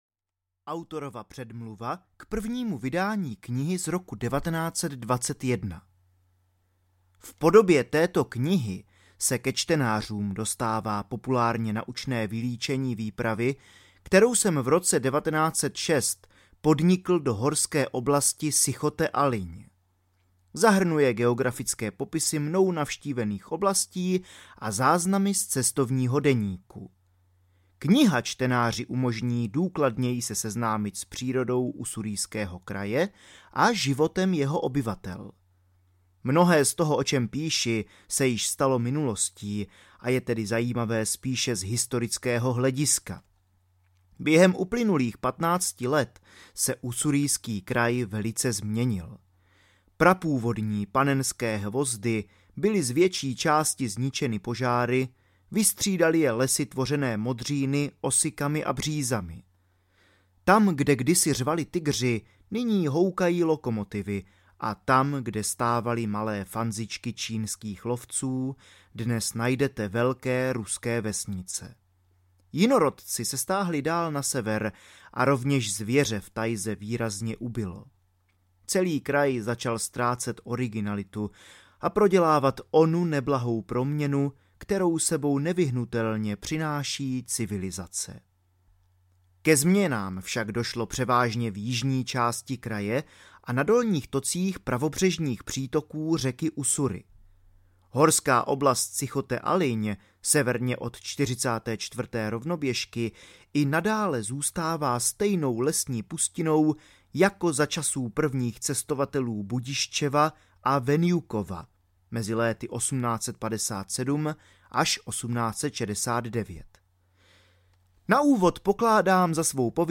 Ussurijským krajem audiokniha
Ukázka z knihy